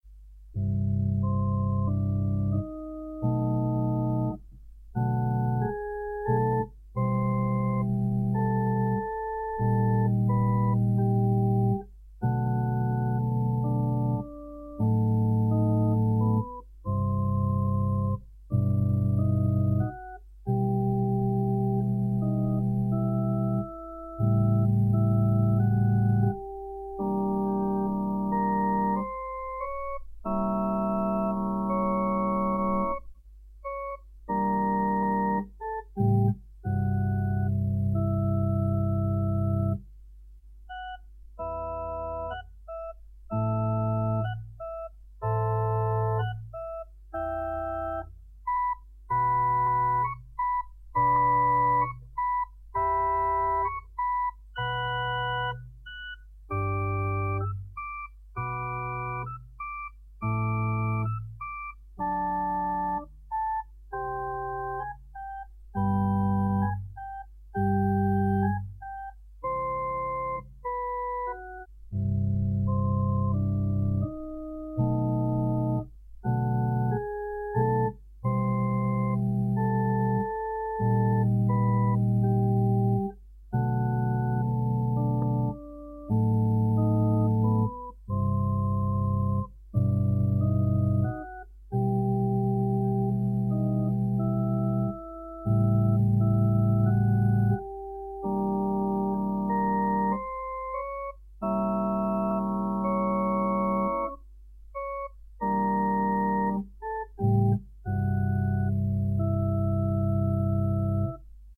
Heimorgel